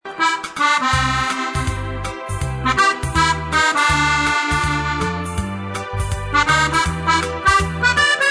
Fisitalia Standard Piano Accordion 37.45 S
Musette
3745s_musette.mp3